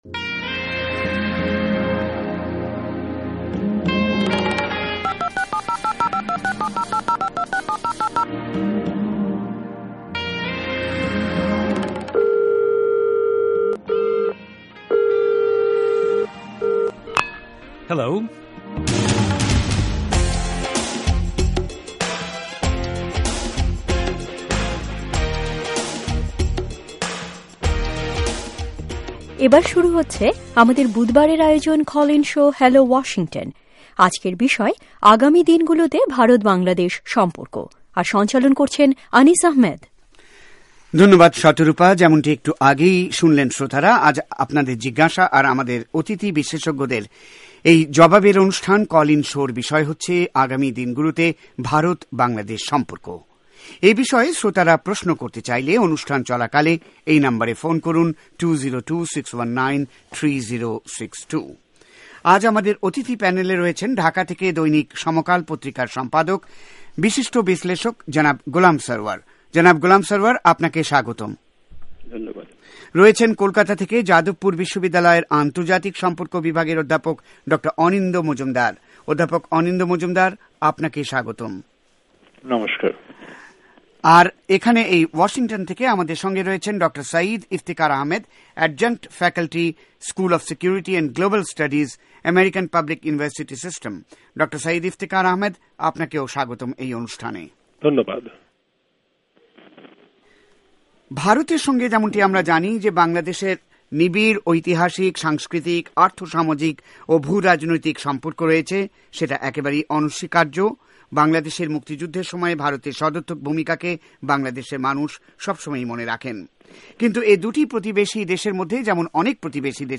শুনুন কল ইন শো